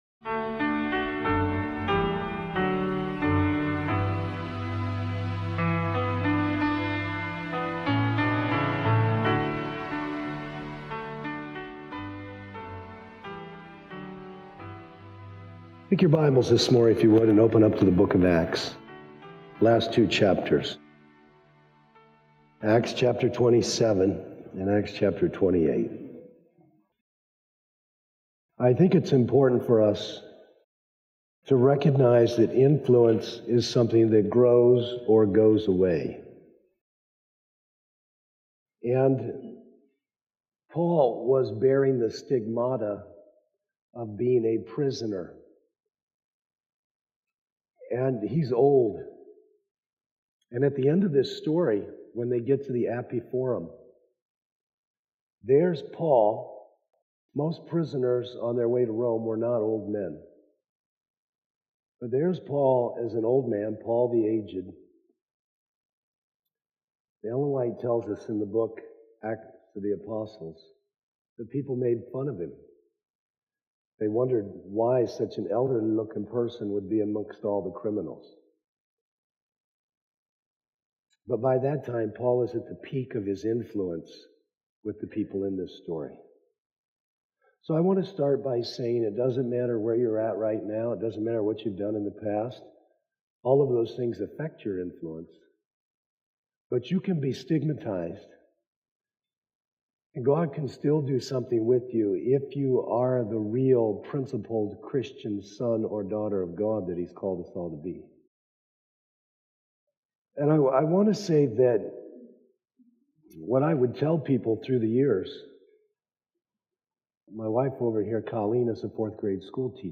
This sermon reveals how true influence flows from faithfulness, integrity, and humility—not position or circumstance—drawing powerful lessons from Paul’s journey and Christlike leadership. It challenges believers to cultivate authentic character in private, speak truth with courage, and live a Spirit-filled life that quietly but powerfully impacts others.